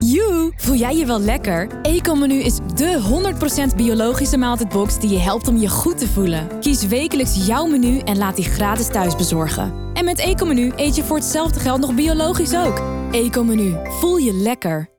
Voice over
Van activerend en motiverend tot inspirerend en rustig; zij probeert altijd met jou te zoeken naar de juiste Tone of Voice en zet graag haar acteertalent in om de radiospot/ reclame/ social content te laten stralen.
Ekomenu Radio demo